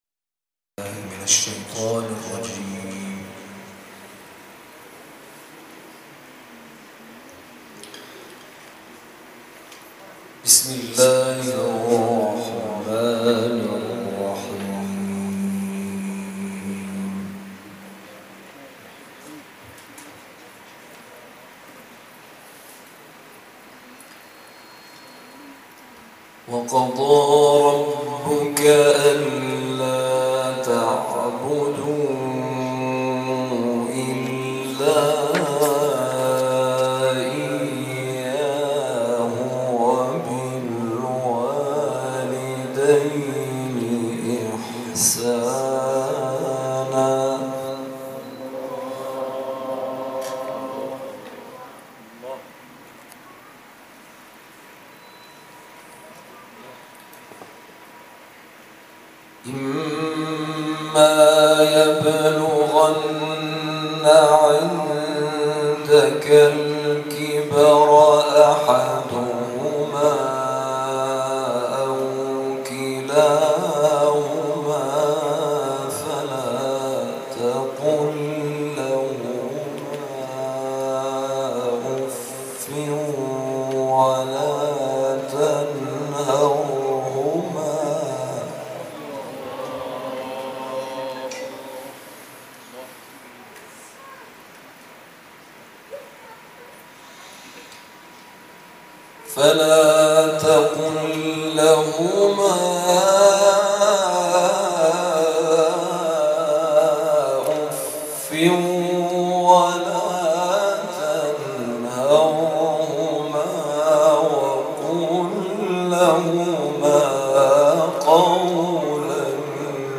گروه فعالیت‌های قرآنی: محفل انس با قرآن کریم، شب گذشته، سیزدهم تیرماه در مسجد امیرالمومنین(ع) ساوه برگزار شد.